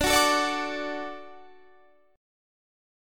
Listen to D strummed